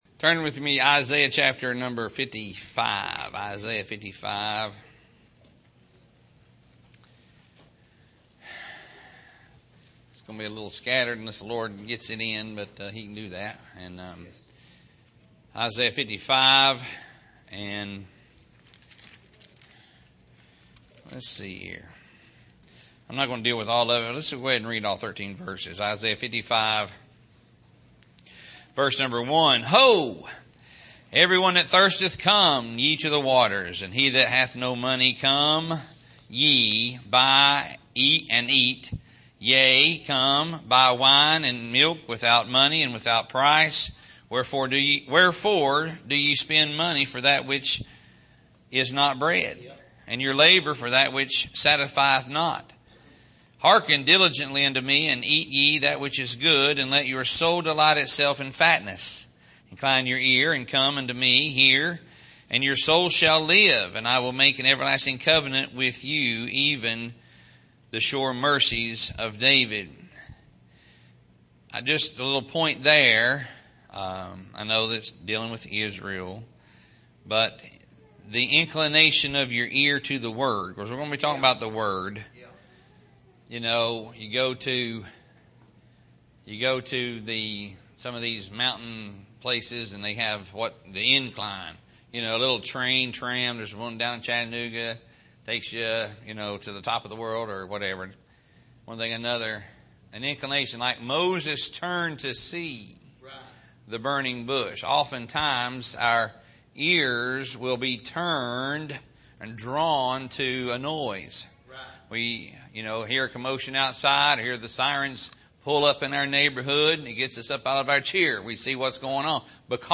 Sermon Archive – Page 44 – Island Ford Baptist Church
Here is an archive of messages preached at the Island Ford Baptist Church.
Service: Sunday Evening